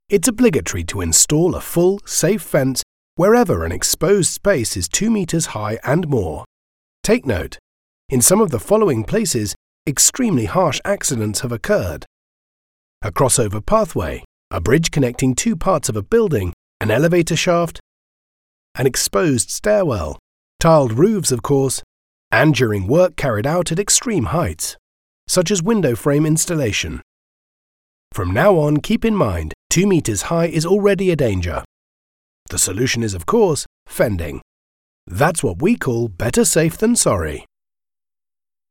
Engels (Brits)
Commercieel, Natuurlijk, Vertrouwd, Warm, Zakelijk
E-learning